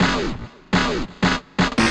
XTRA005_VOCAL_125_X_SC3.wav